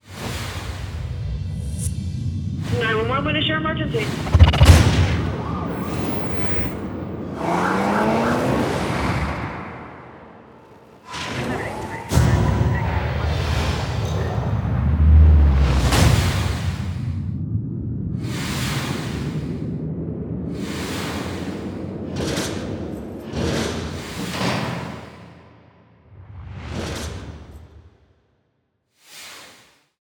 FMM_FA_COMBO_30_ALL New_TOMORROW_ST SFX.wav